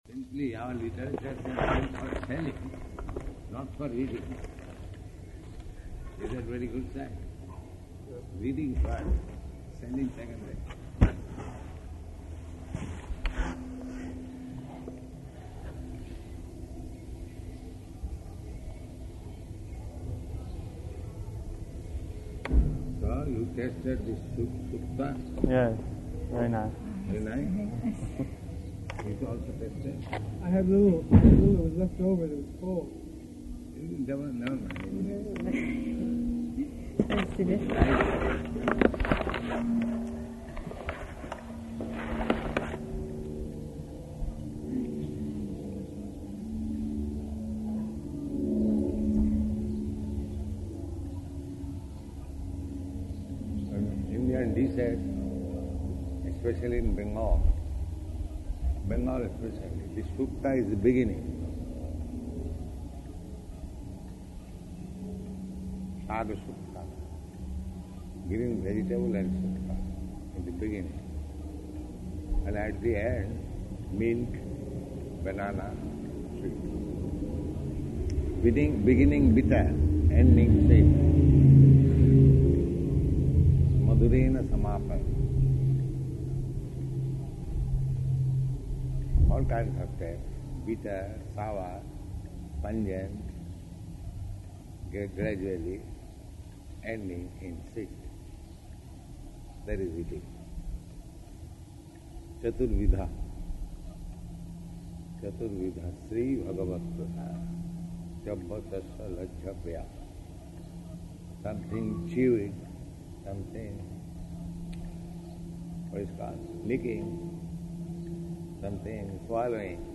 Room Conversation
Type: Conversation
Location: Los Angeles